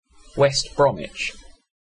West Bromwich (/ˈbrɒmɪ/
En-uk-WestBromwich.ogg.mp3